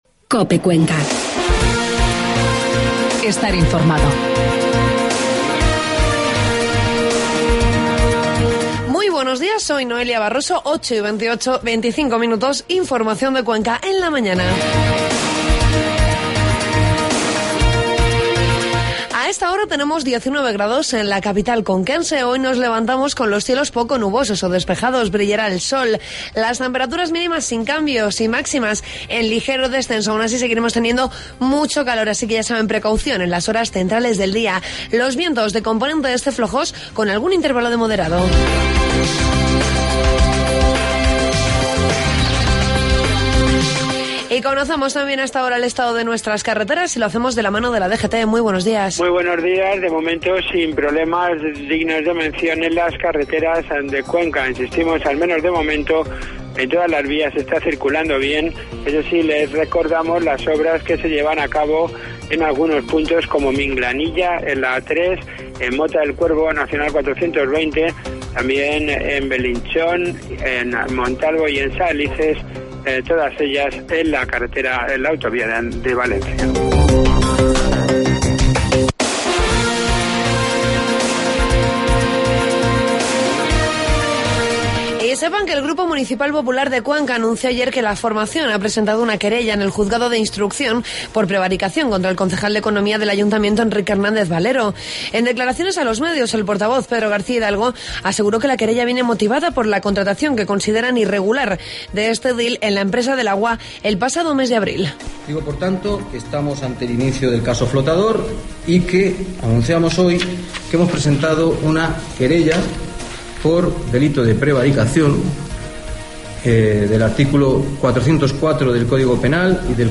Informativo matinal 5 de julio